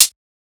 edm-hihat-17.wav